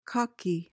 PRONUNCIATION: (KOK-ee) MEANING: adjective: Brashly confident.